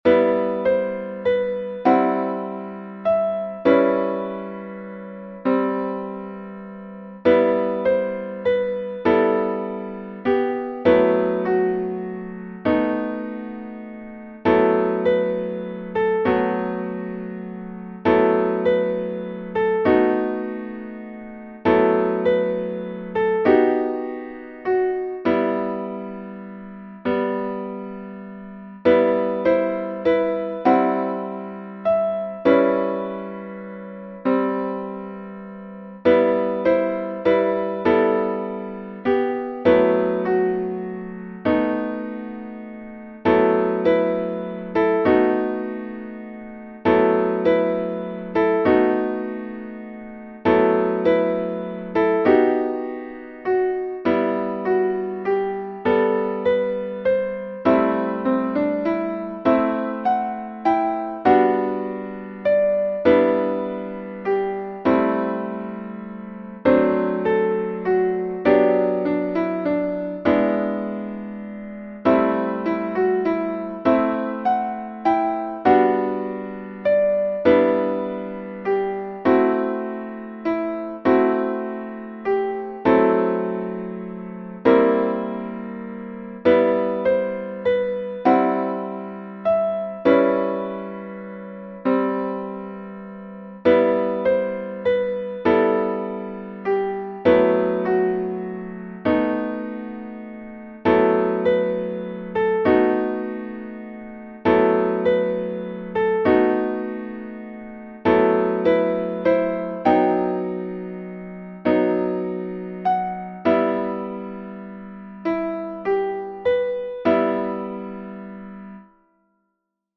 ハンドベル